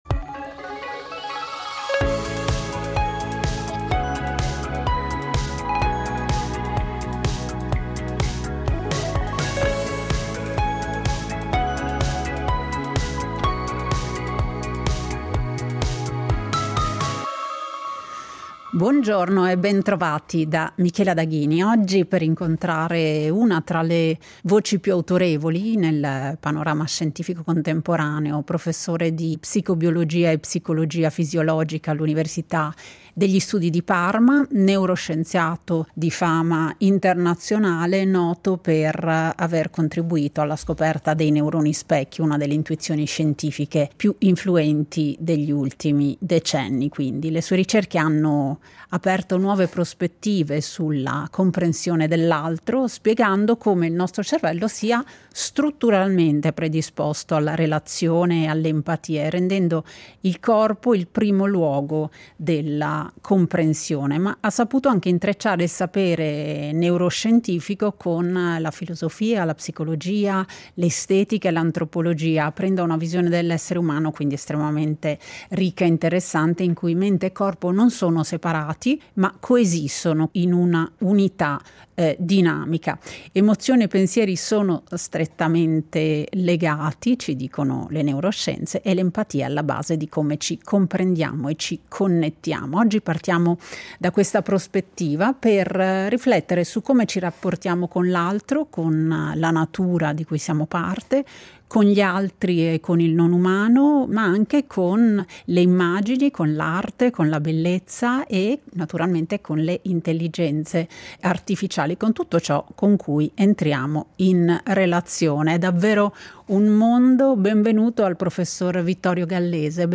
In questa puntata, ospitiamo Vittorio Gallese, neuroscienziato di fama internazionale, tra i protagonisti della scoperta dei neuroni specchio, per un dialogo che attraversa le frontiere tra scienza, filosofia, estetica e antropologia.